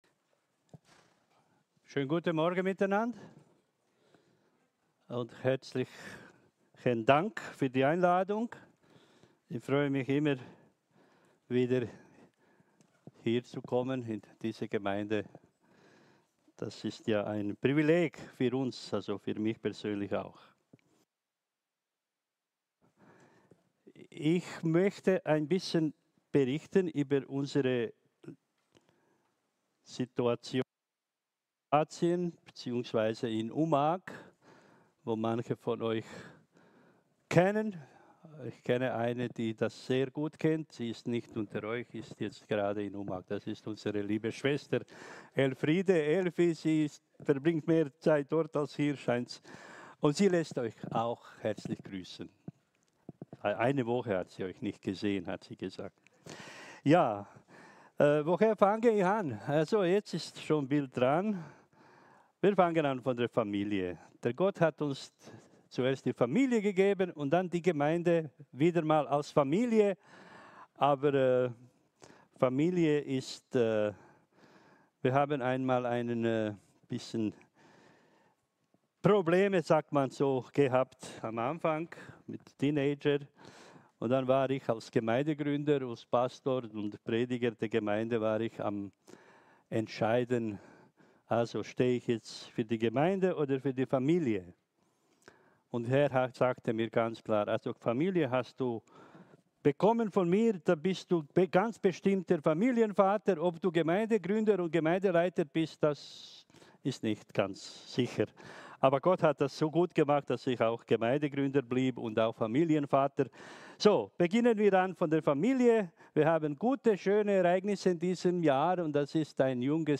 Eine predigt aus der serie "Einzelpredigten 2025."